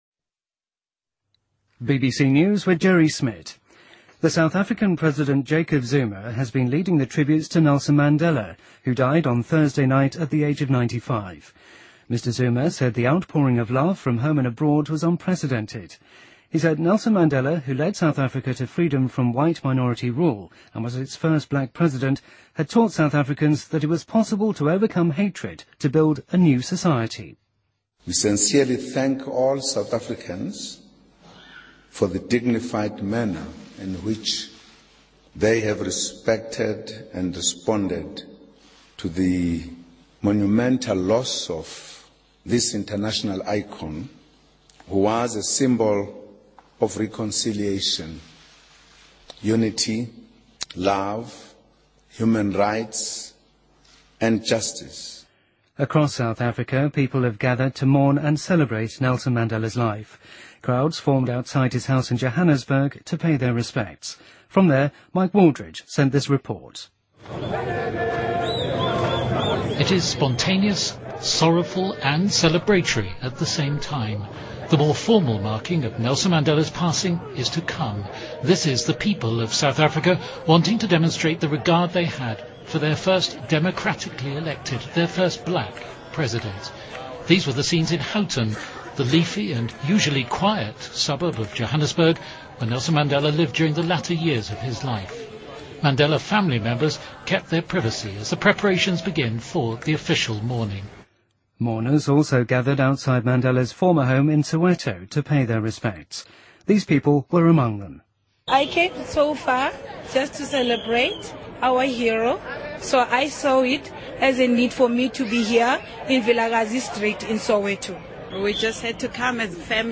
BBC news,2014年世界杯足球比赛抽签分组在巴西揭晓